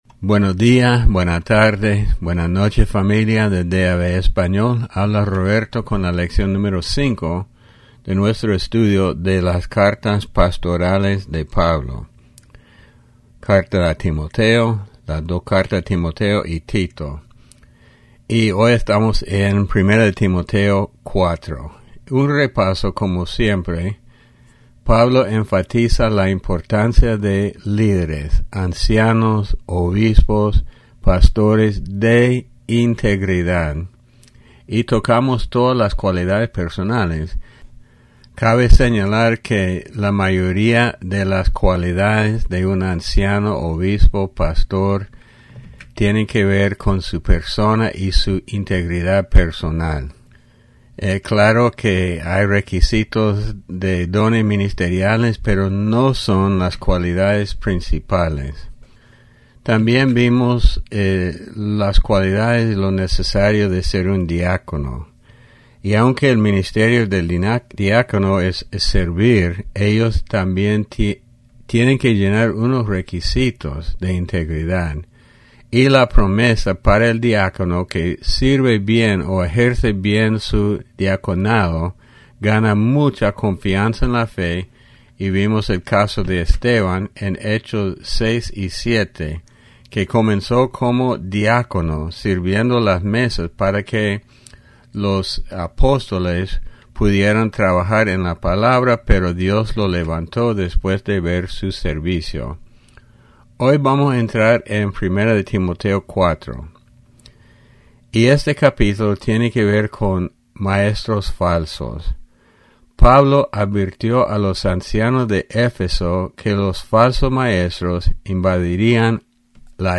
Lección 05 Las Cartas Pastorales (Timoteo y Tito)